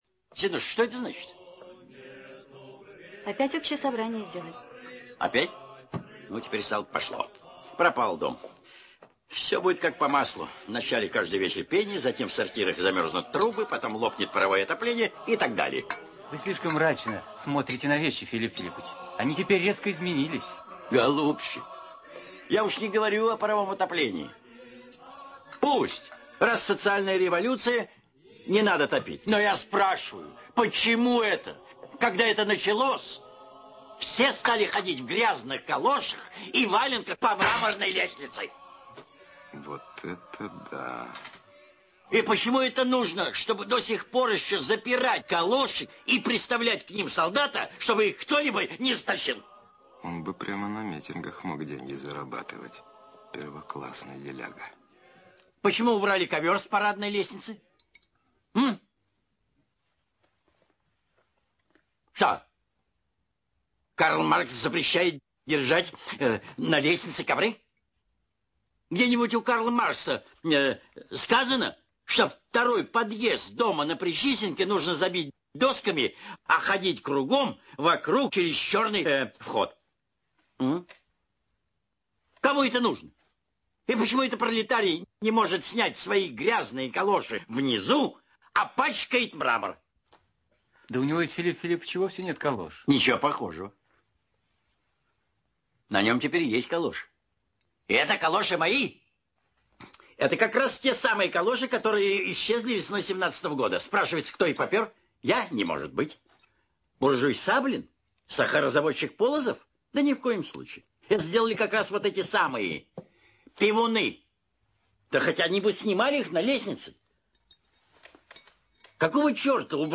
otryvok_iz_k_f_sobach_e_serdce__monolog_professora_preobrazh.mp3